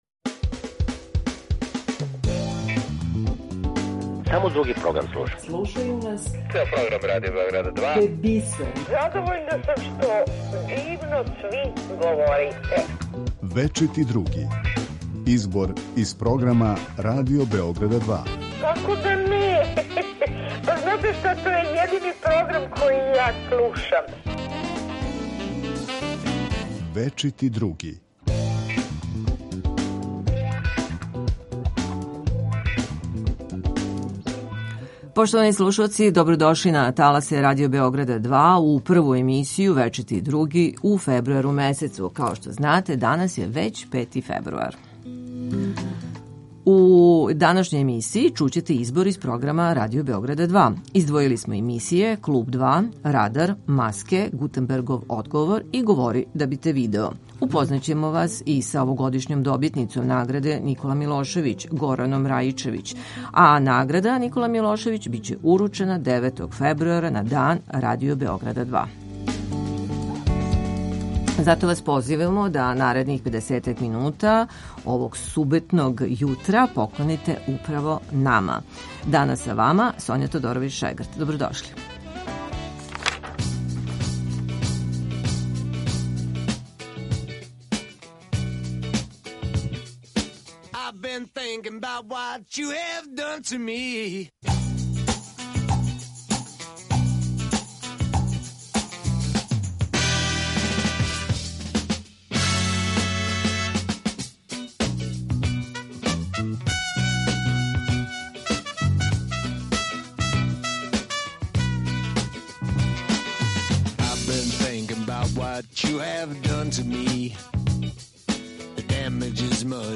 А чућете и наше слушаоце.